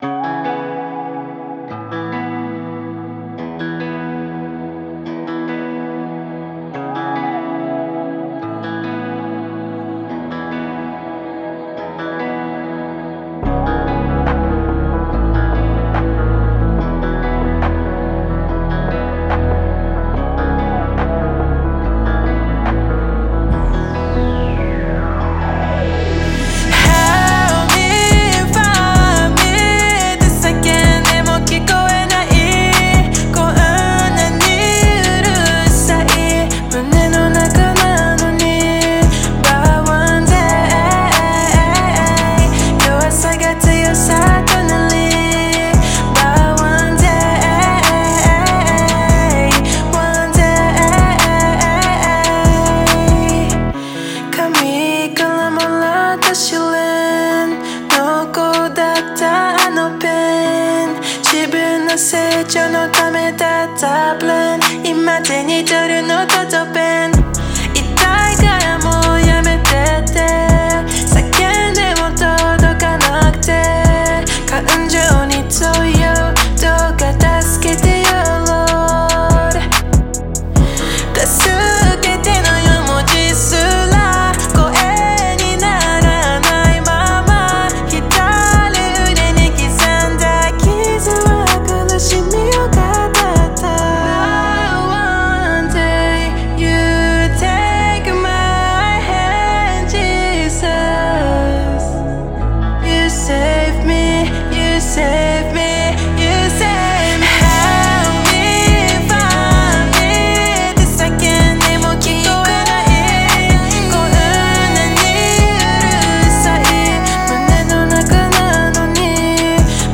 オリジナルKey：「E